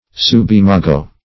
Search Result for " subimago" : The Collaborative International Dictionary of English v.0.48: Subimago \Sub`i*ma"go\, n. [NL.